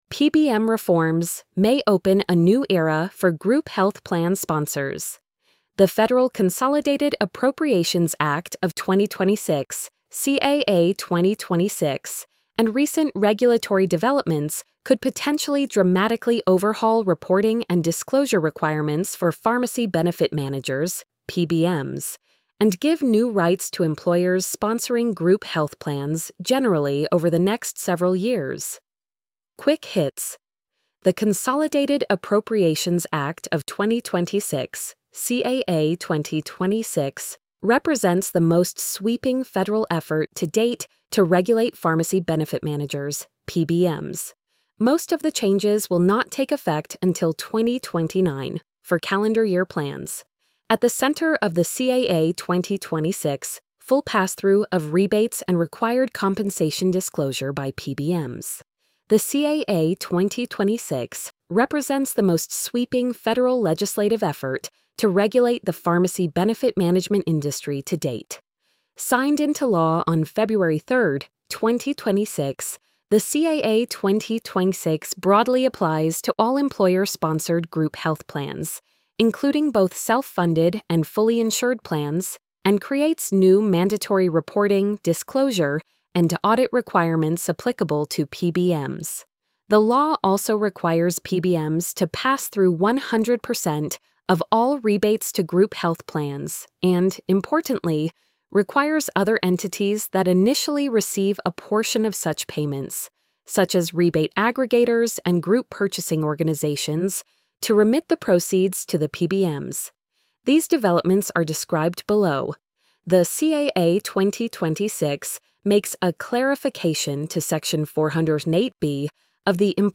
post-86039-tts.mp3